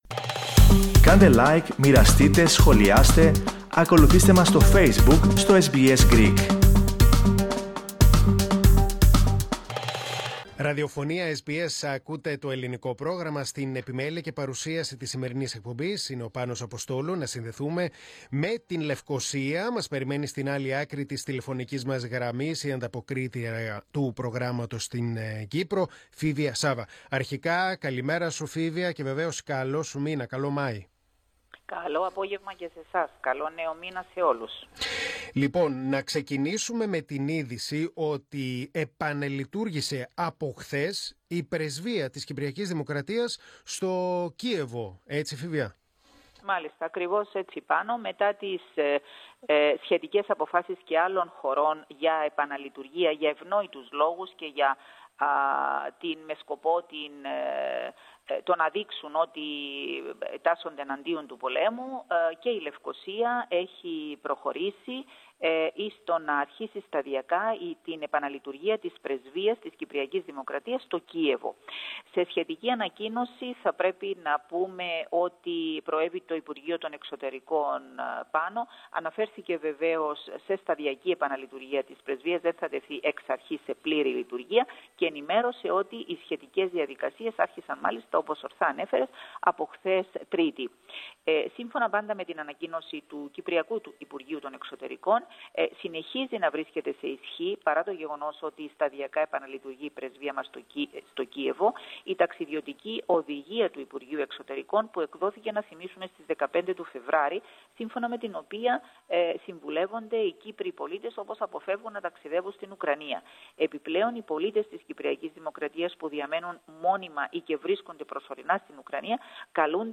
Ακούστε, ολόκληρη την ανταπόκριση από την Κύπρο, πατώντας το σύμβολο στο μέσο της κεντρικής φωτογραφίας.
antapokrisi_kypros_0.mp3